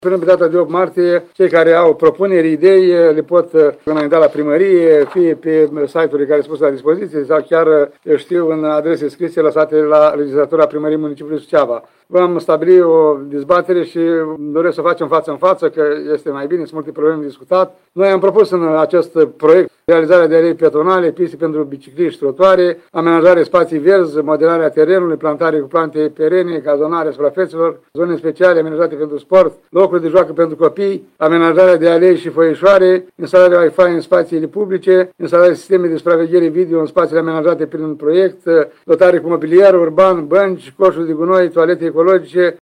Primarul ION LUNGU a precizat cu suprafața care va fi modernizată nu poate depăși 10 % din totalul de 43 hectare ale parcului.